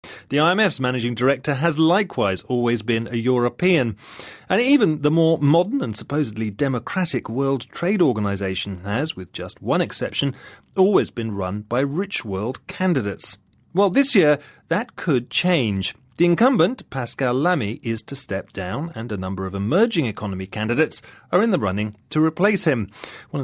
【英音模仿秀】世贸组织总干事竞选 听力文件下载—在线英语听力室